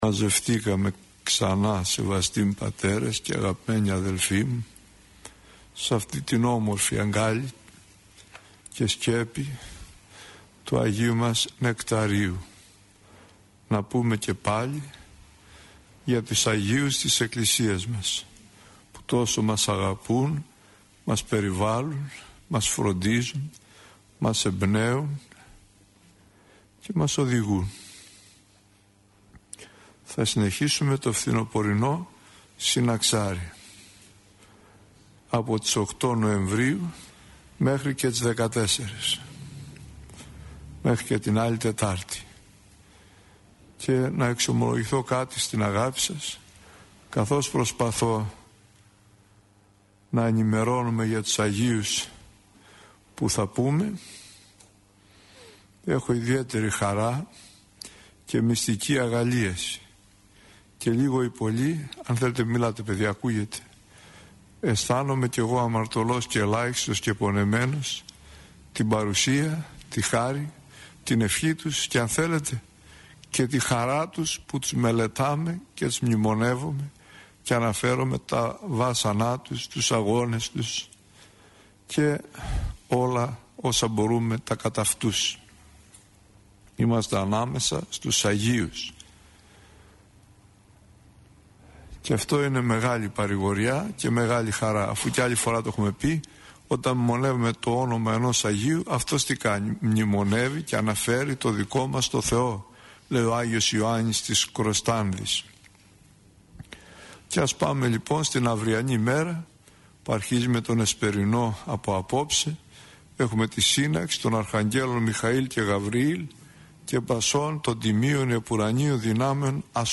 Η εν λόγω ομιλία αναμεταδόθηκε από τον ραδιοσταθμό της Πειραϊκής Εκκλησίας.